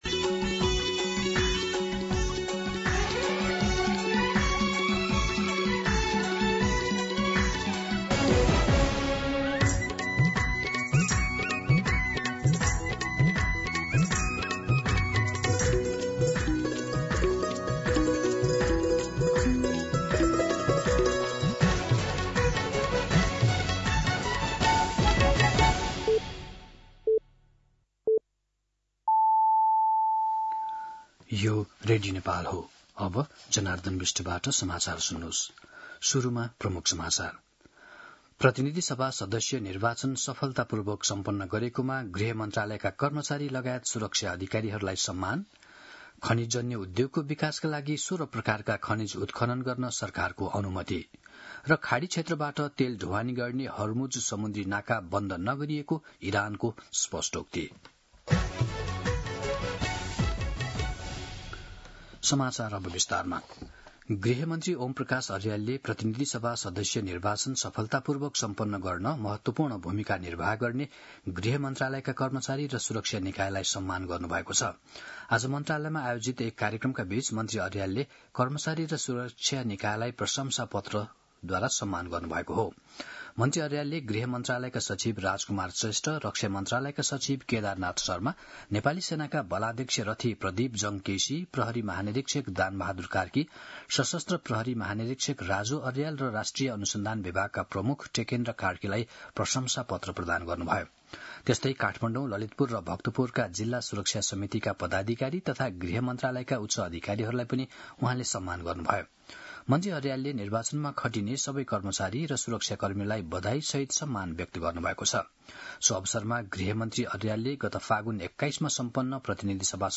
दिउँसो ३ बजेको नेपाली समाचार : ९ चैत , २०८२
3pm-News-09.mp3